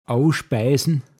Wortlisten - Pinzgauer Mundart Lexikon
abfertigen mit leeren Worten åospaisn